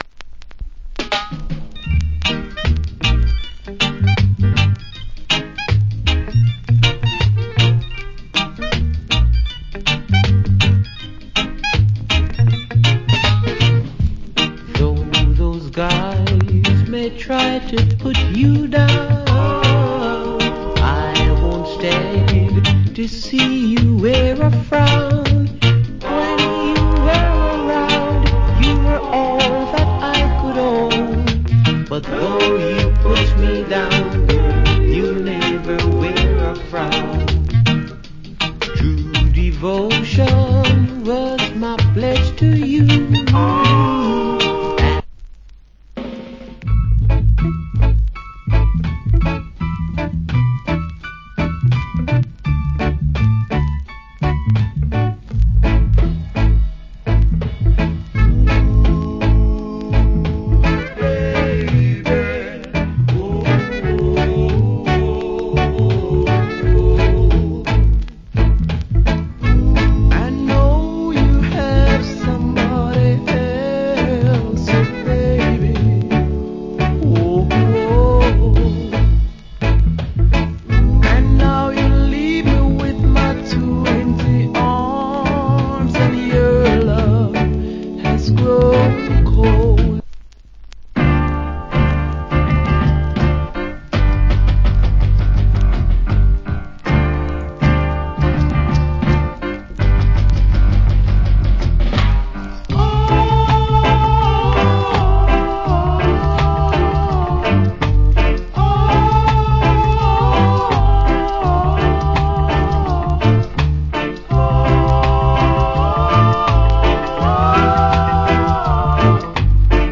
Rock Steady To Early Reggae.